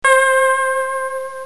cartoon15.mp3